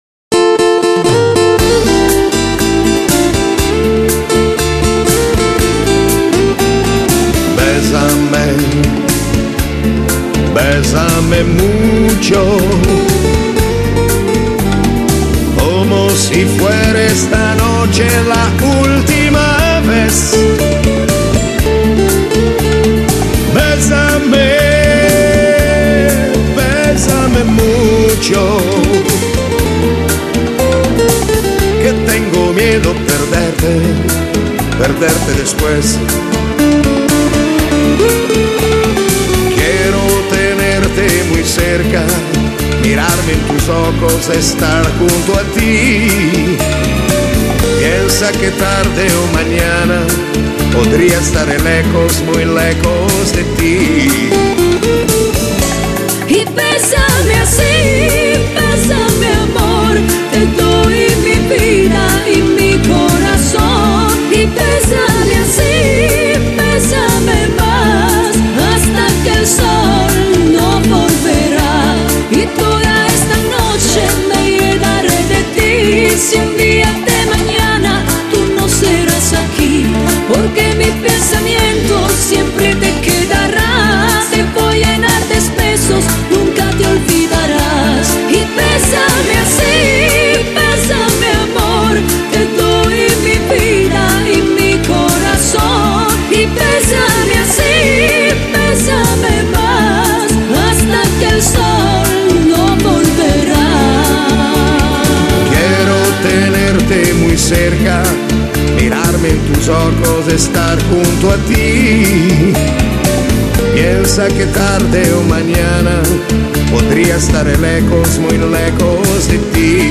Genere: Bachata